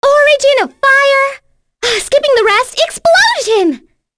Cleo-Vox_Skill7_c.wav